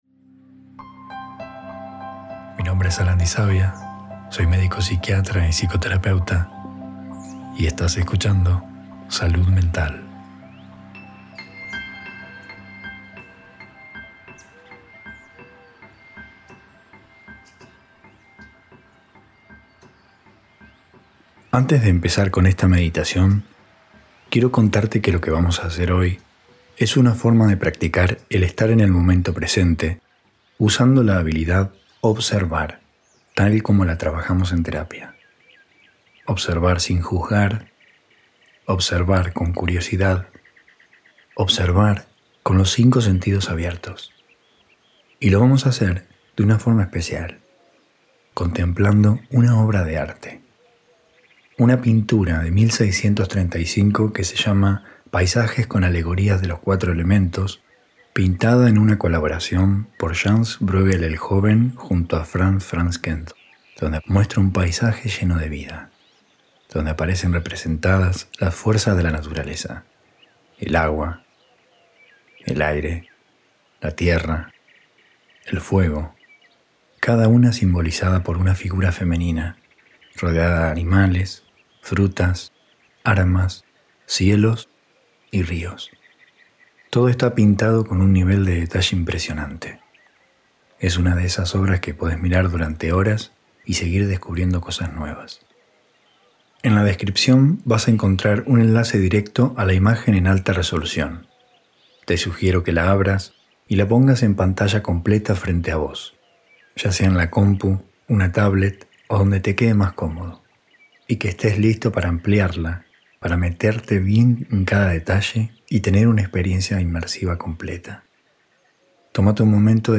Meditación contemplativa✨ Paisaje con alegorías de los cuatro elementos (1635)